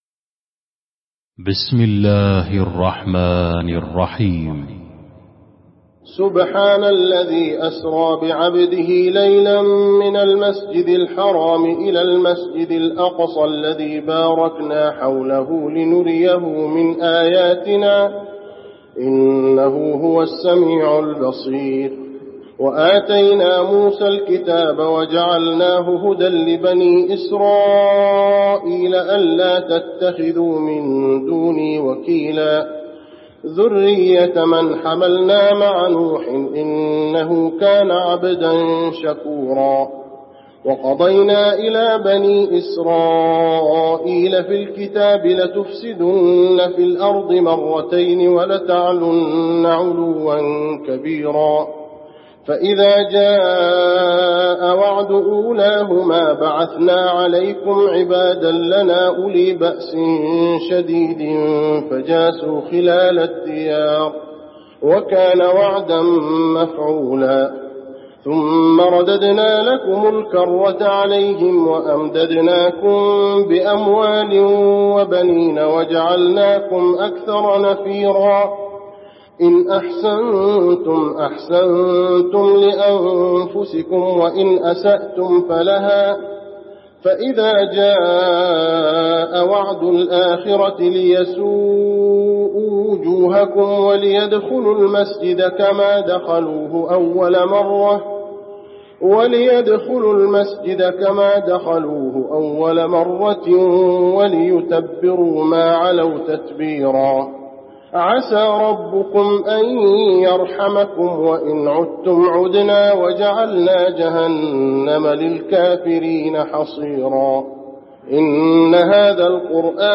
المكان: المسجد النبوي الإسراء